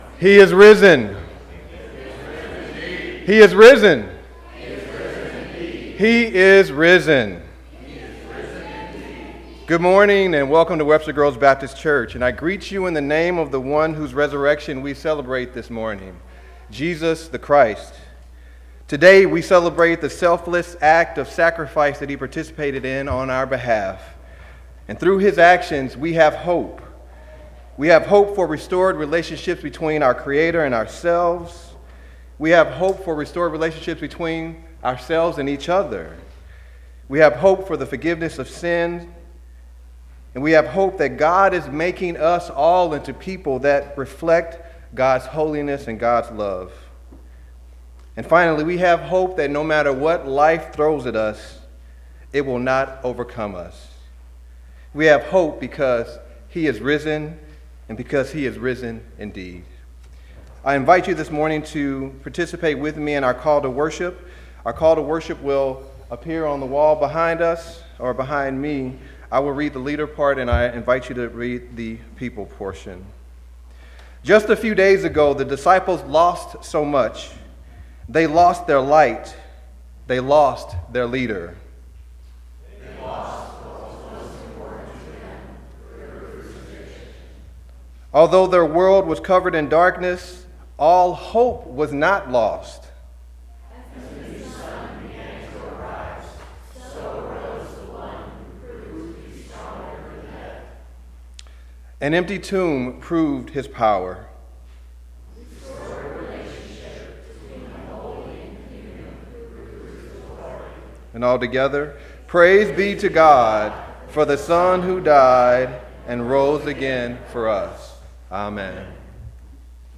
wgbc-4-21-19-easter.mp3